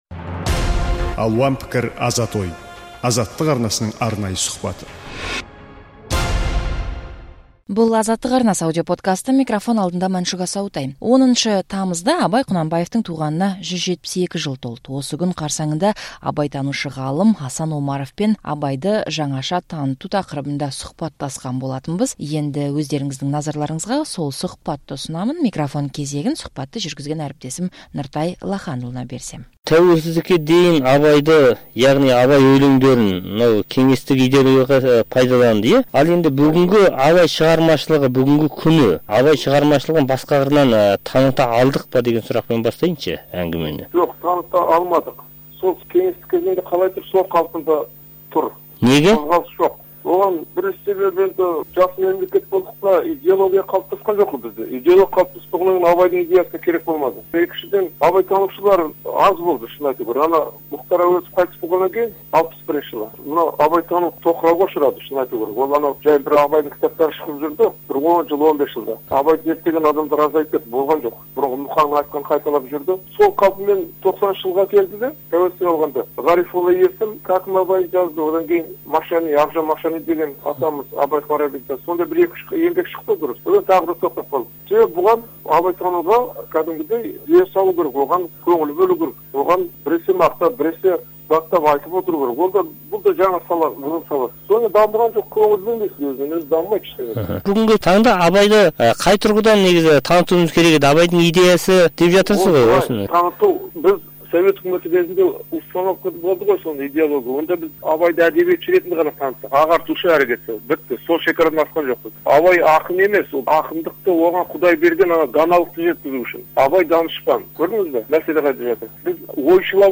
сұхбаттастық